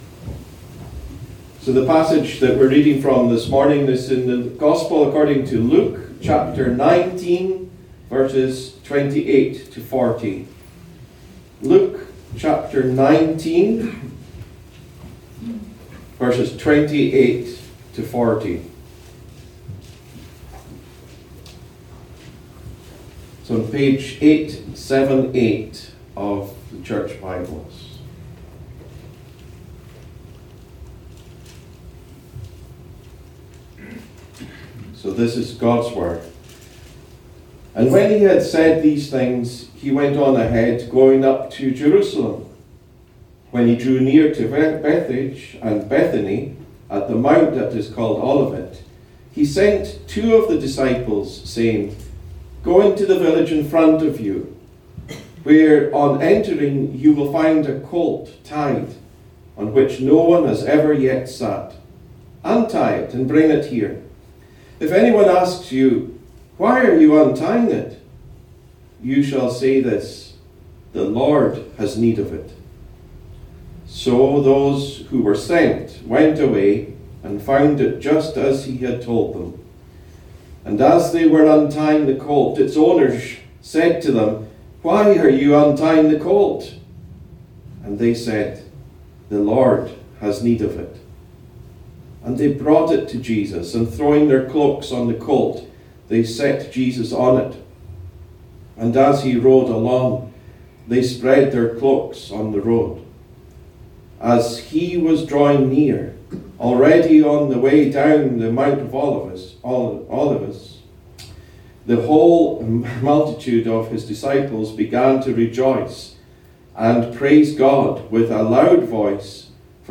A link to the video recording of the 11:00am service, and an audio recording of the sermon.
Series: Individual sermons